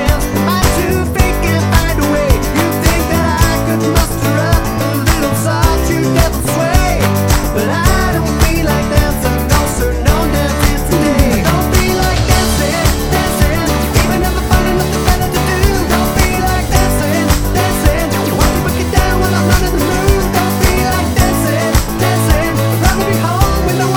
No Two Part Harmony Pop (2000s) 4:08 Buy £1.50